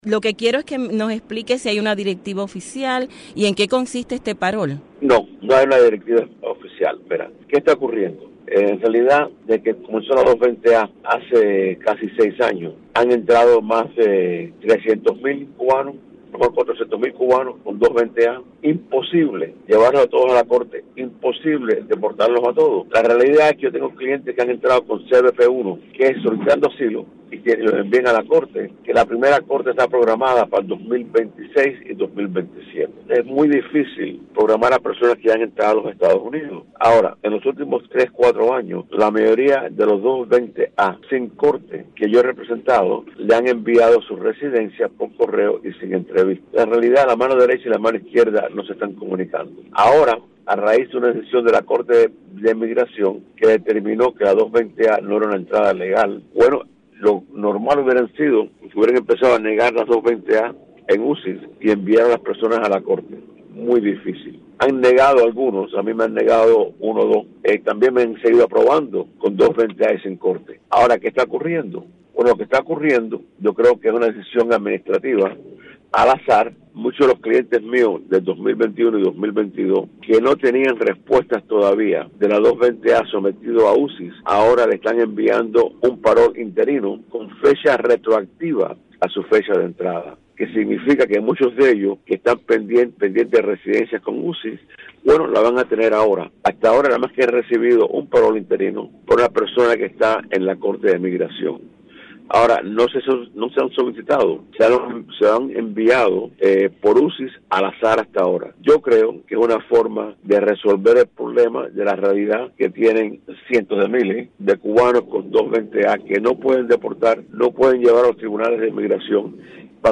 Entrevista
en la revista informativa Martí Noticias AM